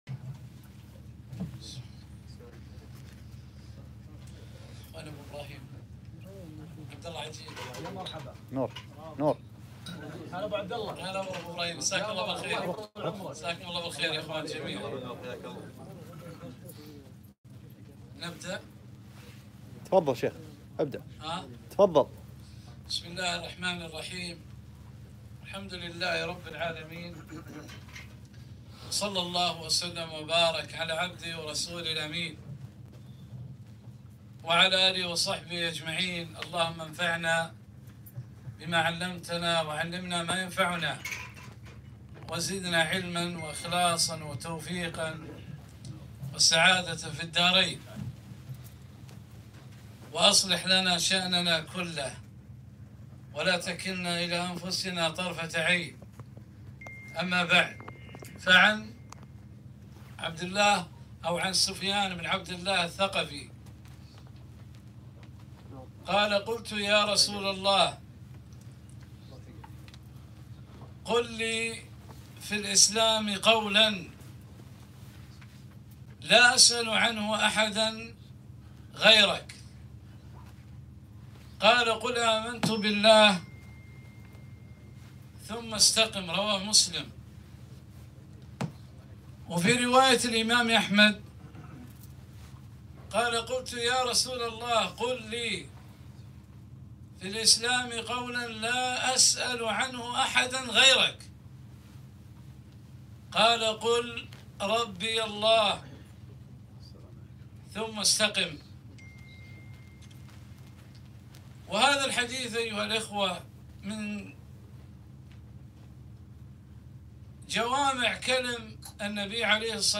محاضرة - (قل لي في الاسلام )